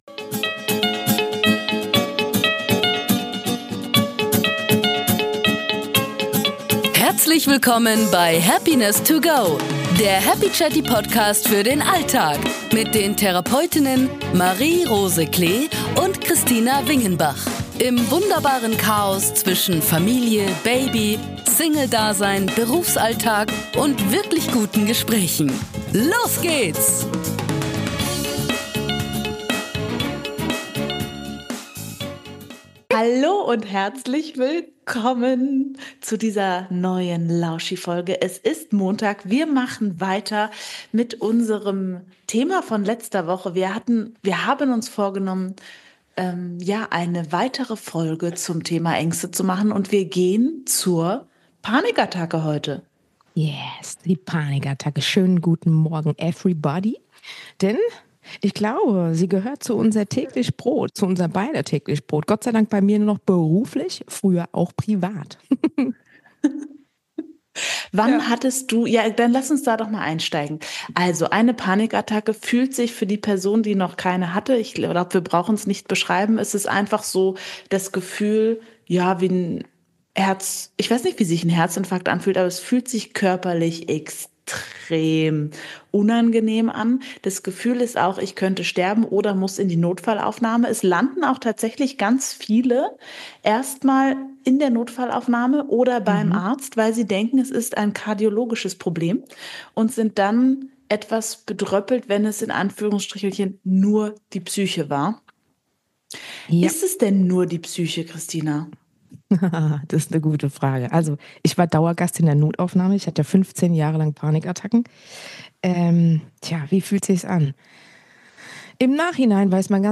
In "Happiness to go" nehmen euch zwei Therapeutinnen mit auf eine Reise durch die Höhen und Tiefen des Alltags und der Therapie.